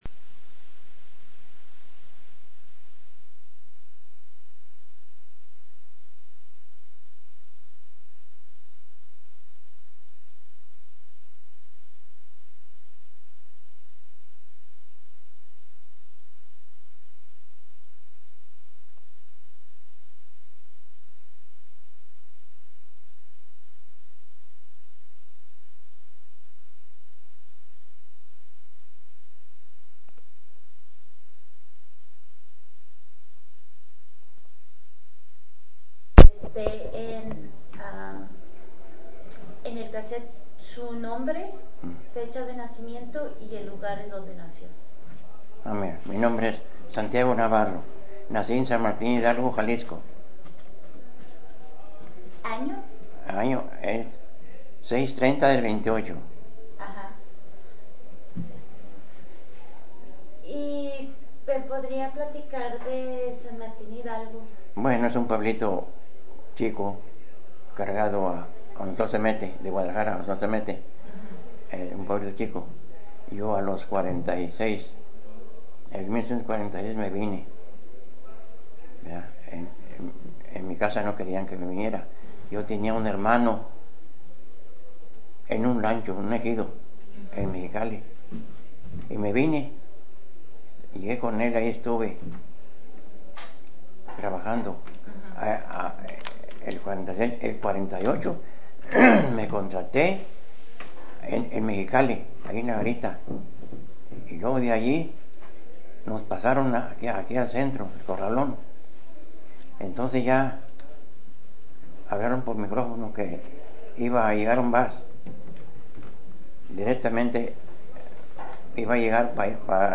bracero
Original Format Mini Disc